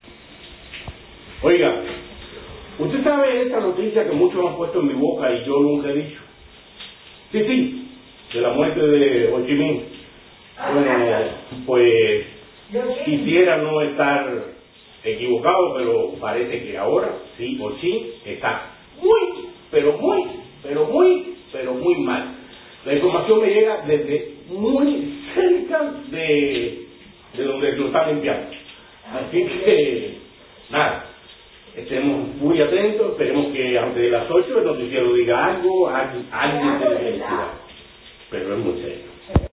durante una conexión improvisada en sus redes sociales, mientras tomaba una ducha